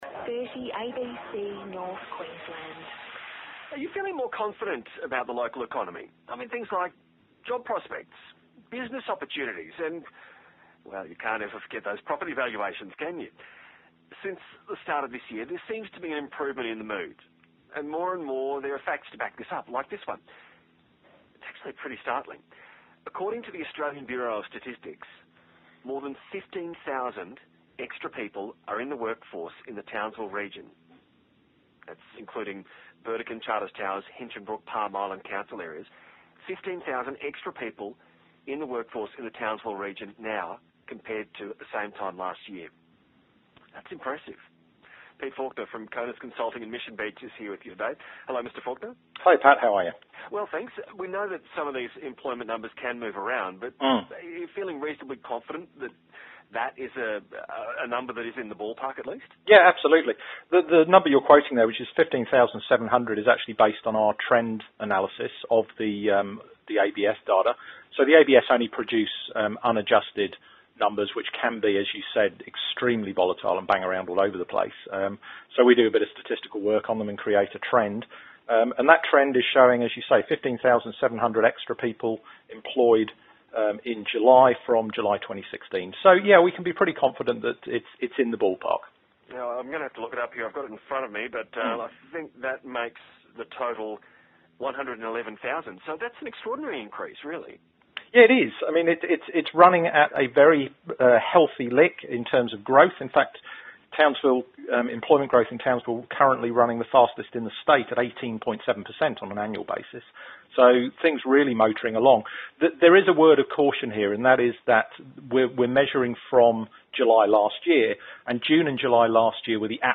Talking to ABC North QLD about Townsville jobs numbers